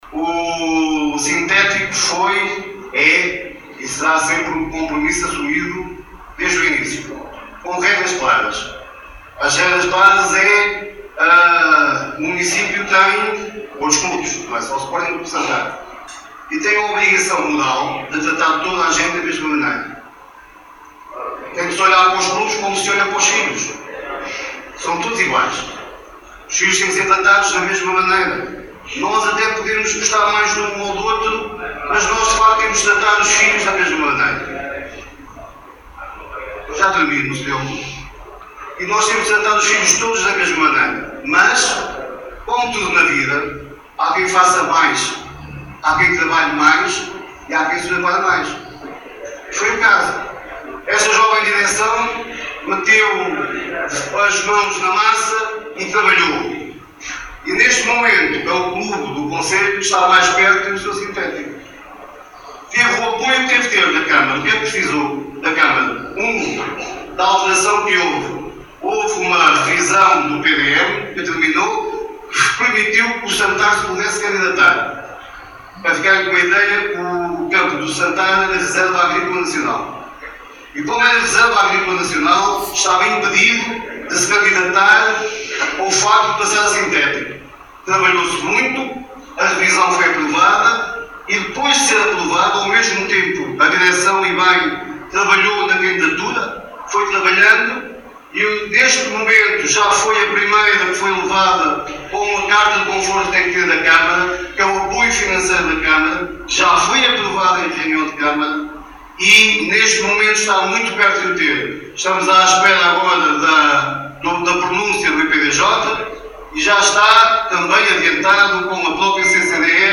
Este domingo, 27 de julho, realizou-se na sede do Sporting Clube de Santar o almoço do 48º aniversário, onde reuniu Associados, Dirigentes, Representante da Associação de Futebol de Viseu, Presidente da União de Freguesias de Santar e Moreira e Presidente da Câmara Municipal de Nelas.
Joaquim Amaral, Presidente da Câmara Municipal de Nelas, no seu discurso confirmou o compromisso e o apoio da autarquia para que a relva sintética no campo do Estádio do Sporting Clube de Santar seja uma realidade em breve.